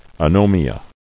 [a·no·mi·a]